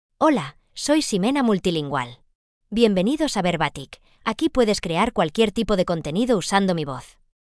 Ximena MultilingualFemale Spanish AI voice
Ximena Multilingual is a female AI voice for Spanish (Spain).
Voice sample
Listen to Ximena Multilingual's female Spanish voice.
Female
Ximena Multilingual delivers clear pronunciation with authentic Spain Spanish intonation, making your content sound professionally produced.